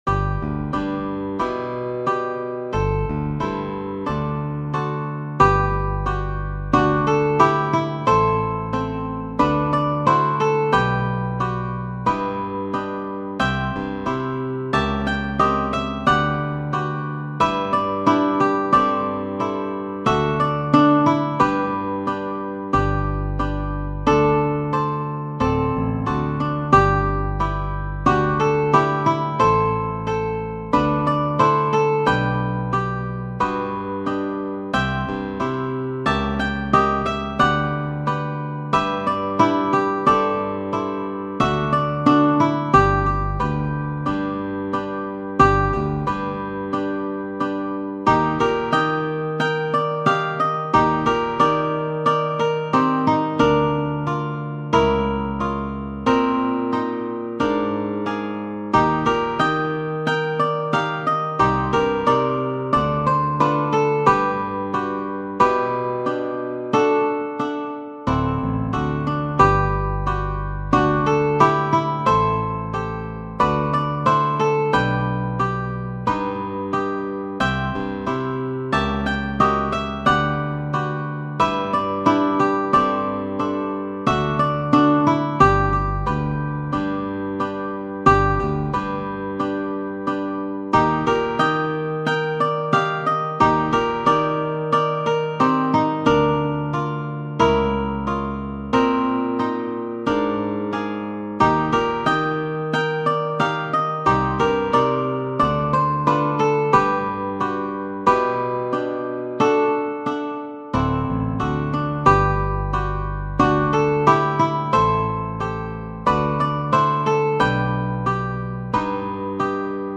.mp3 File - Full Quartet